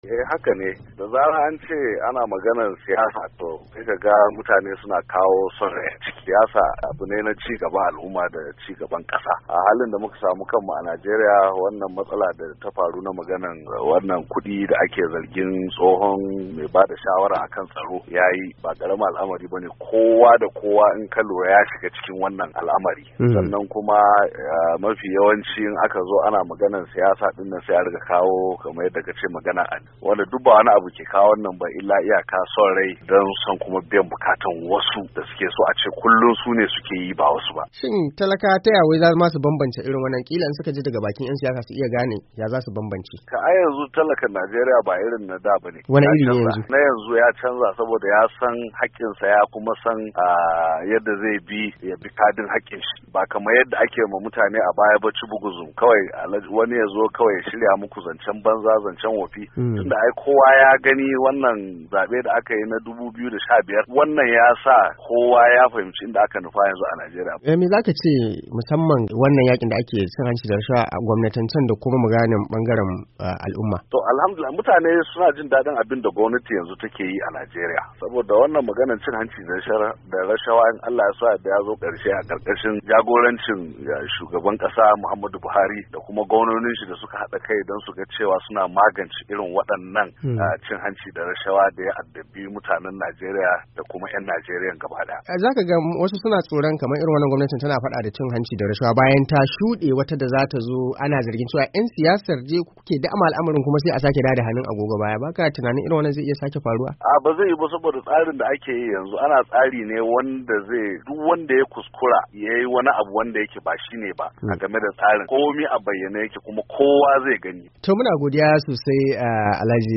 Muryar Amurka ta zanta da Alhaji Abdullahi Garba Abbas tsohon shugaban karamar hukumar Giwa dake jihar Kaduna wanda ya bayyana yadda 'yan siyasa ke anfani da addini da kabilanci da bangaranci domin cimma muradun kansu.